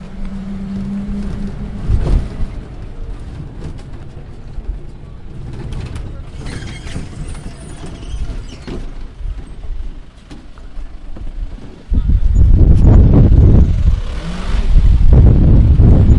描述：波哥大直布罗陀公共街道（“Avenida 19”）的交通（哥伦比亚）以XY技术（44.1 KHz）排列的Zoom H4录制
Tag: 音景 环境 交通 现场录音 城市